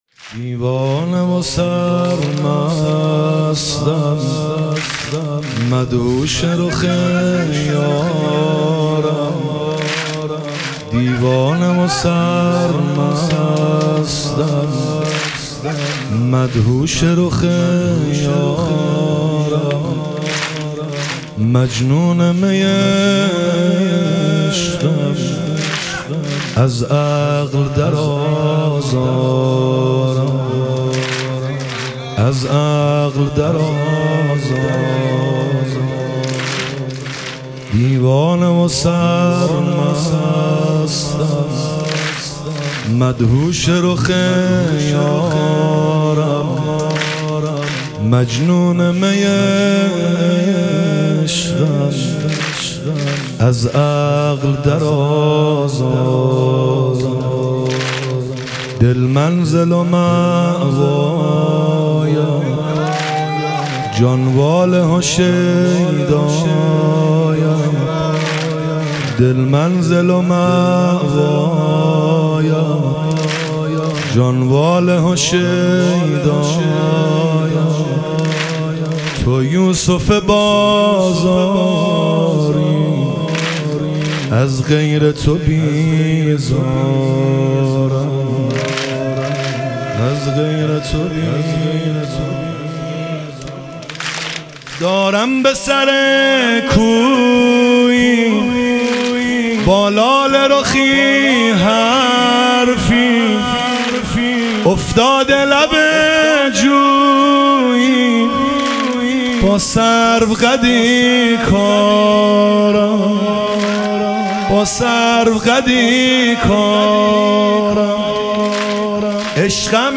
شهادت حضرت خدیجه علیها سلام - واحد